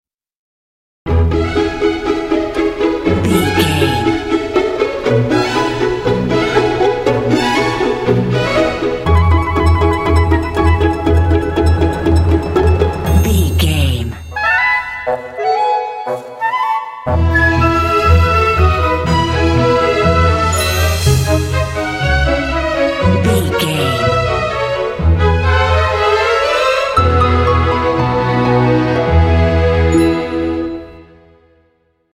Ionian/Major
magical
bouncy
strings
flute
harp
oboe
orchestra
cinematic